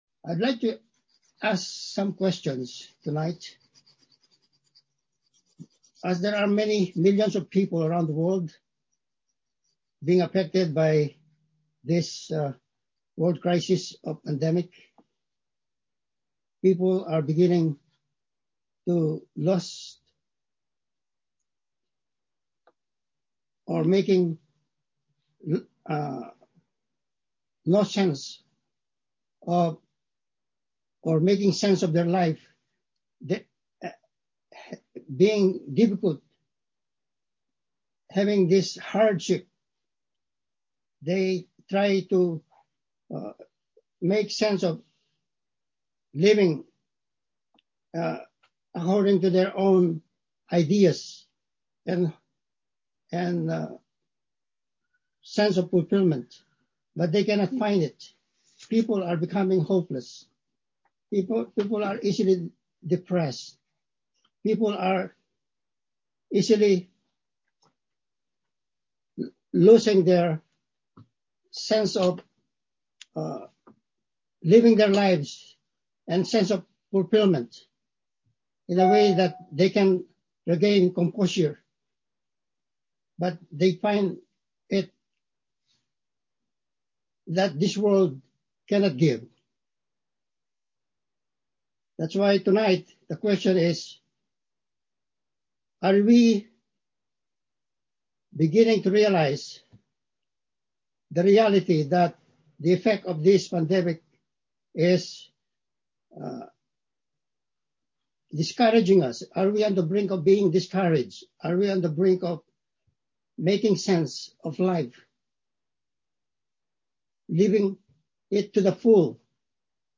Passage: Galatians 2:20 Service Type: Sunday Evening Service